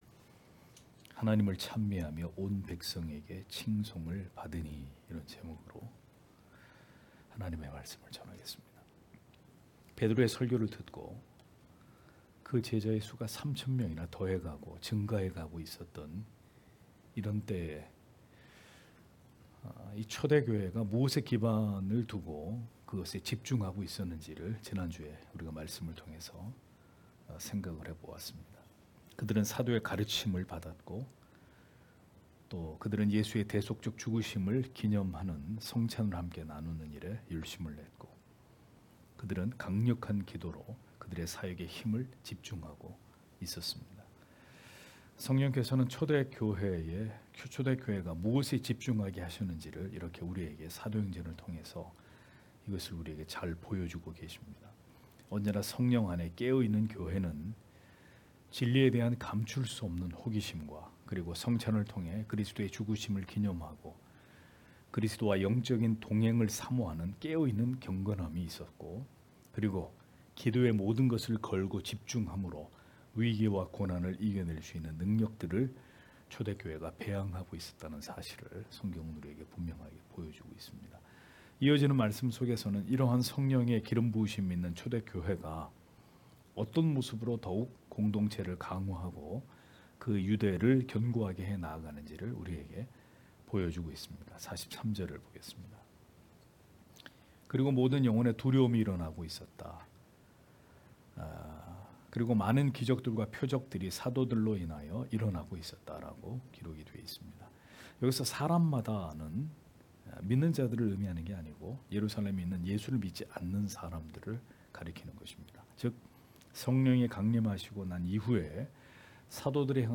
금요기도회 - [사도행전 강해 19] 하나님을 찬미하며 또 온 백성에게 칭송을 받으니 (행 2장 43-47절)